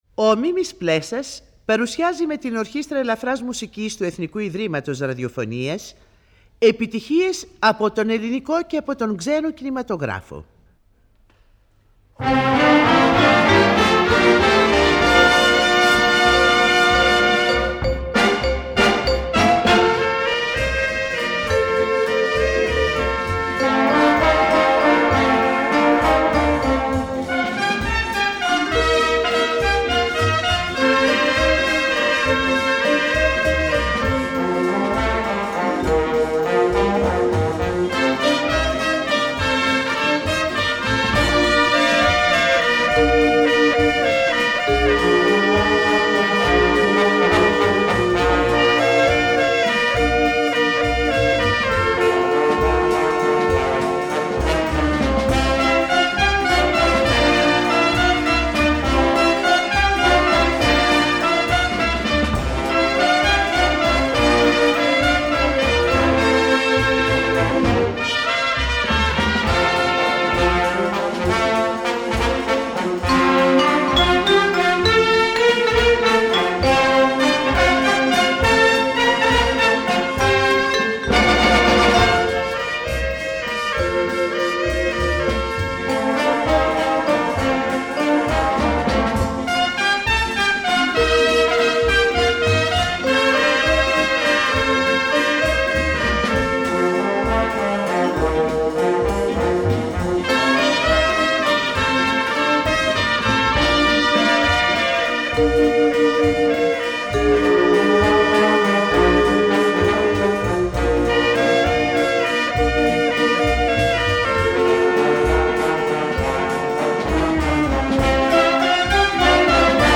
μία εκπομπή του 1967